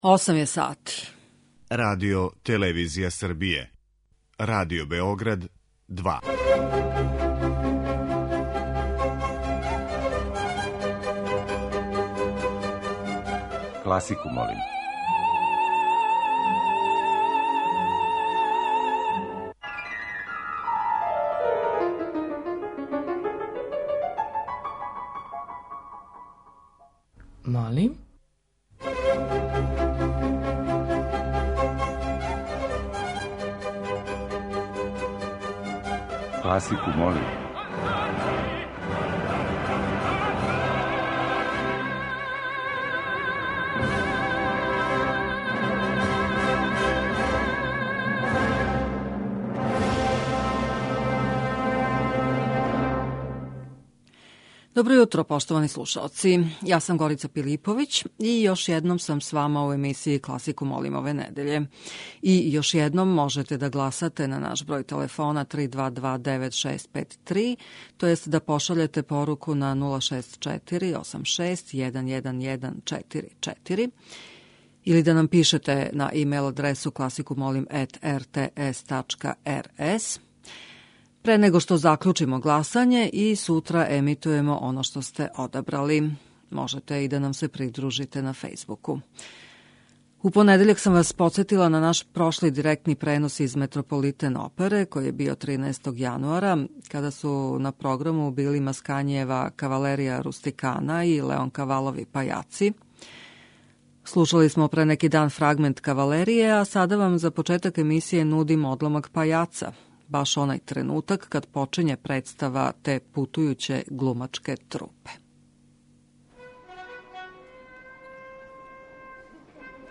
Недељна топ-листа класичне музике Радио Београда 2